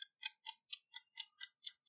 Make watch_tick loopable
sounds_watch_tick.ogg